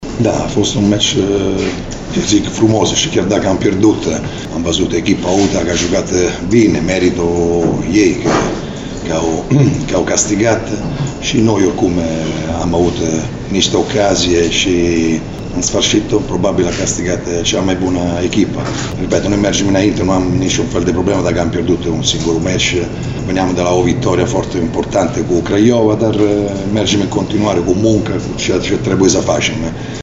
Antrenorul celor de la Sepsi, Cristiano Bergodi, a recunoscut că UTA a fost echipa superioară și a reușit să se impună în acest meci de luptă grație calităților masive pe care le au unii jucători:
Bergodi-UTA-a-meritat-victoria.mp3